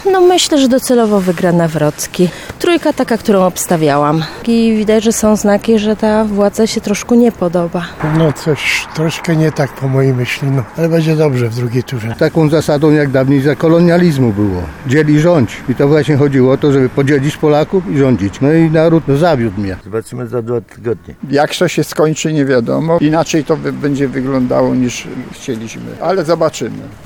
19sonda-wybory.mp3